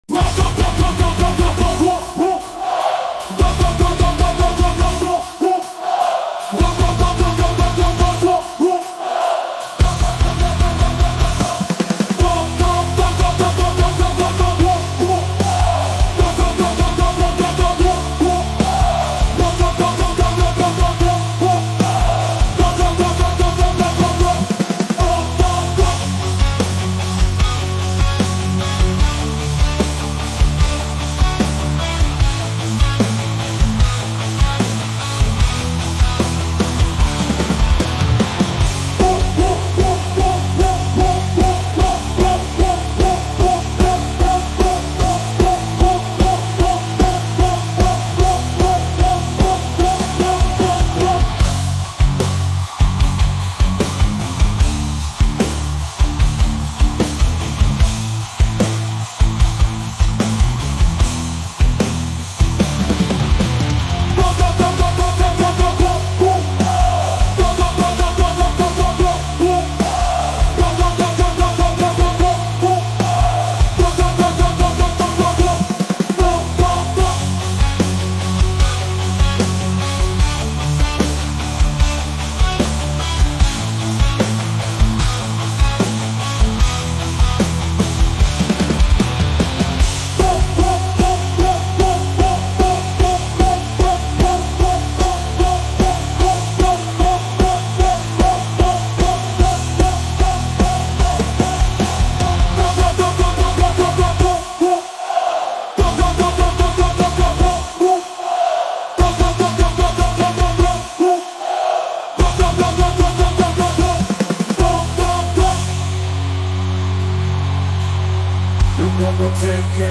One user provided the following lyrics to make a metal song:
The Dog song - 100% generated by AI